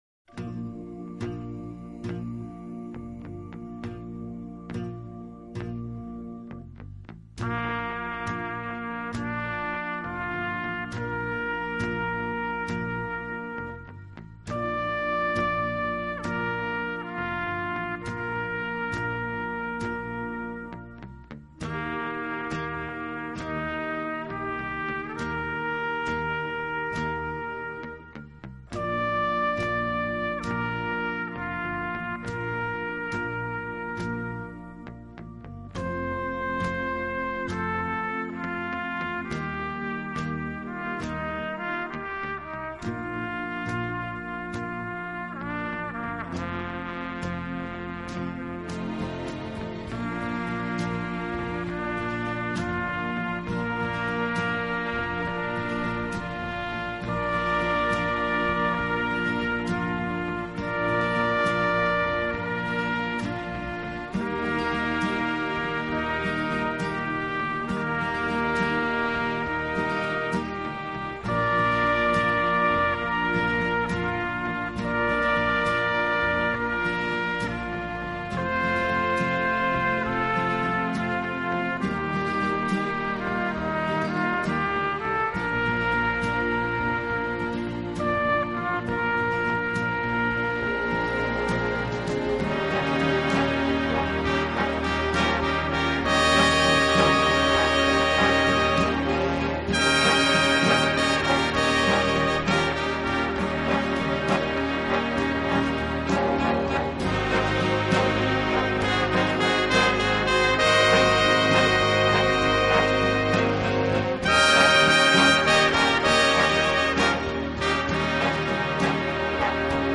【轻音乐】
茂，富于浪漫气息。温情、柔软、浪漫是他的特色，也是他与德国众艺术家不同的地方。